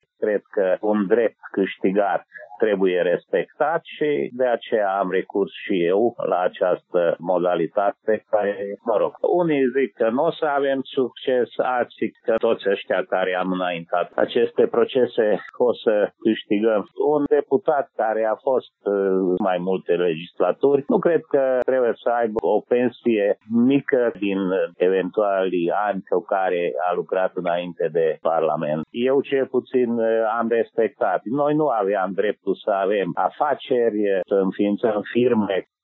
Fostul deputat UDMR, Kelemen Attila, care a fost timp de 20 de ani în Parlament, deplânge inexistența unei legislații specifice în România pe acest subiect, așa cum există în alte state sau în Parlamentul European: